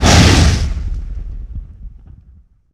punch1.wav